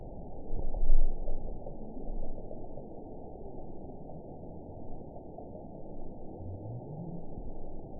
event 922179 date 12/27/24 time 22:27:05 GMT (11 months, 1 week ago) score 9.42 location TSS-AB06 detected by nrw target species NRW annotations +NRW Spectrogram: Frequency (kHz) vs. Time (s) audio not available .wav